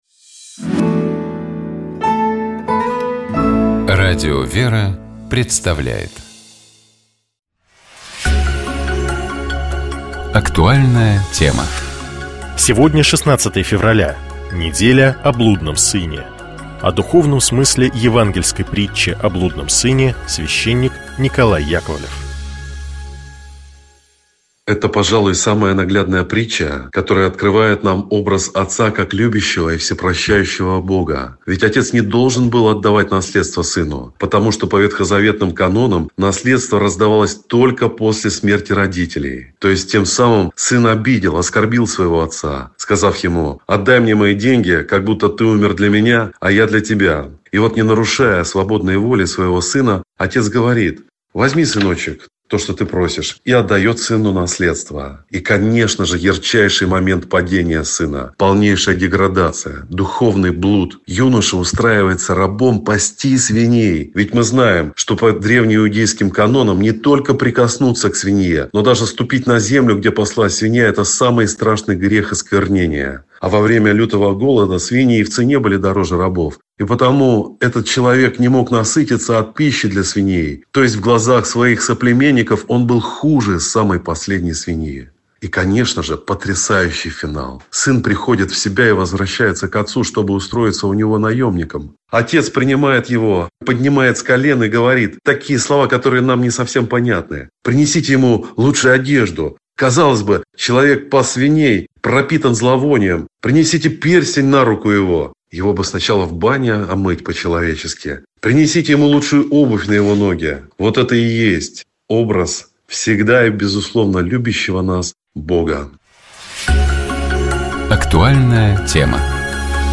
О духовном смысле евангельской притчи о блудном сыне — священник